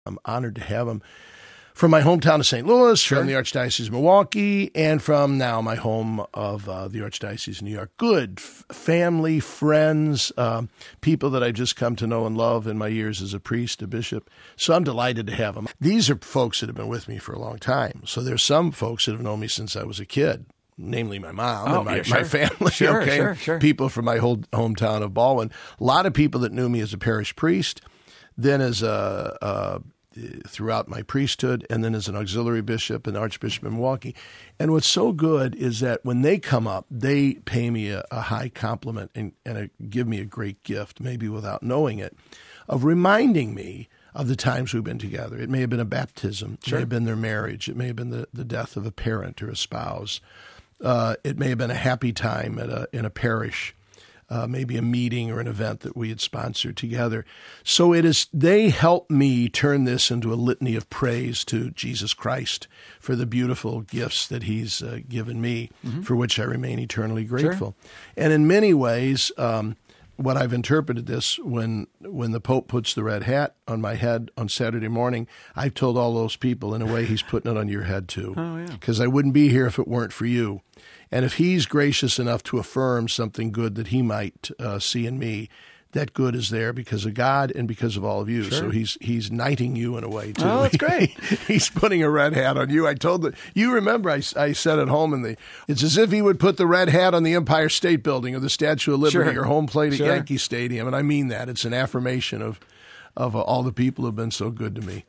Archbishop Timothy Dolan of New York will receive his Cardinal’s ring and red hat in the February 18 Consistory. In an interview in Vatican Radio studios, he said some 1000 friends and family have come to Rome from his home town of St. Louis, the Archdiocese of Milwaukee, and New York to take part in the celebrations and show their closeness to the cardinal-to-be.